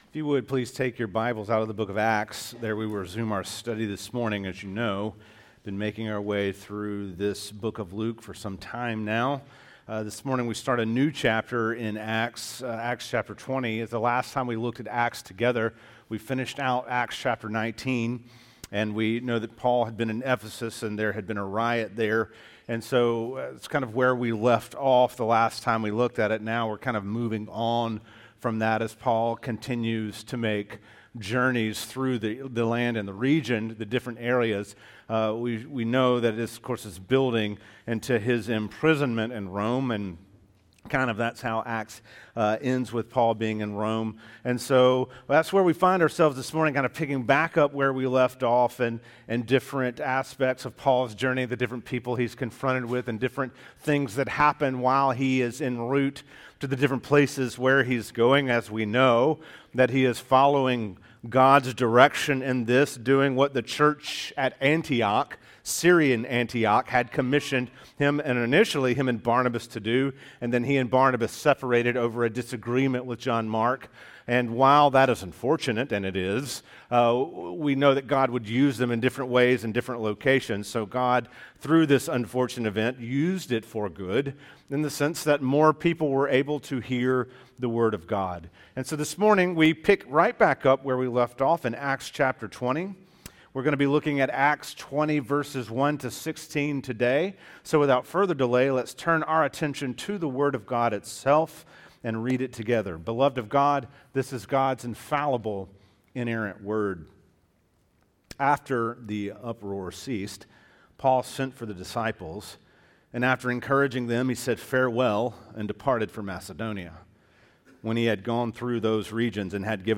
teaches from the series